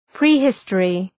Προφορά
{prı’hıstərı}